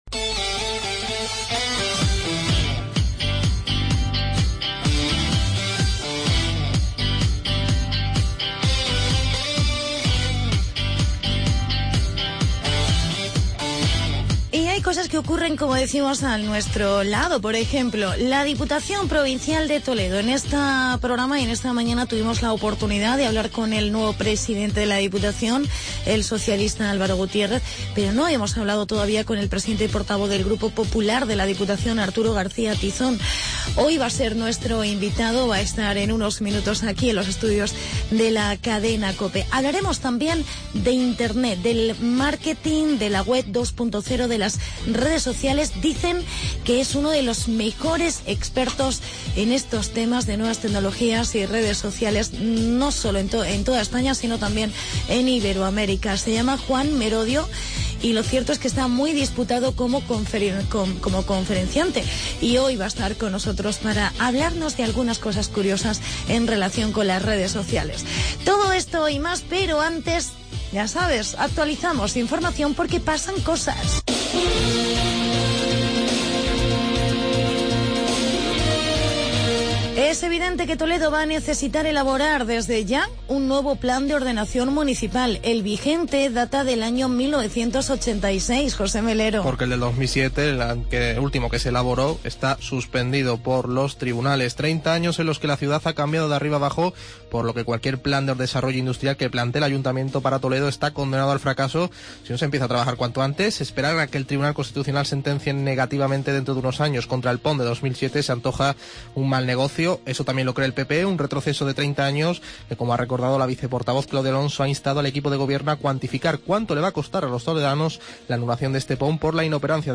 Hablamos con el portavoz del PP en la diputación de Toledo, Arturo García Tizón y con el experto en Redes Sociales